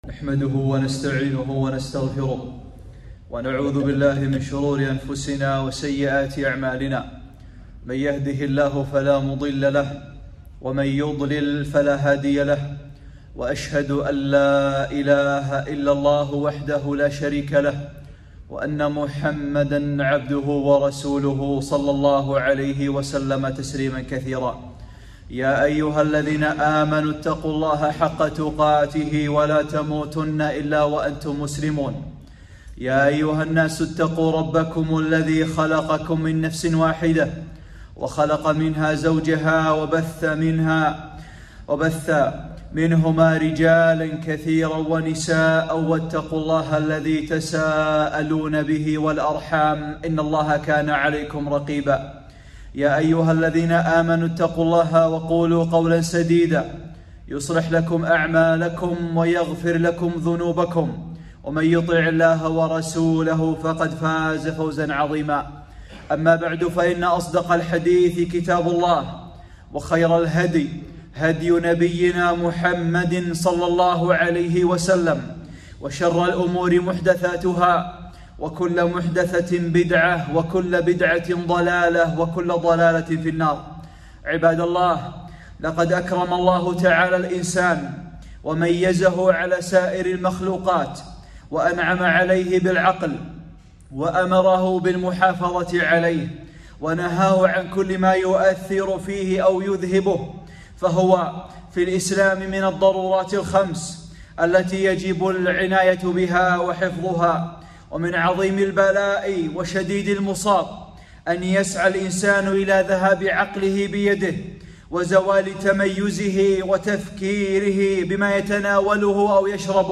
خطبة - خطورة المسكرات والمخدرات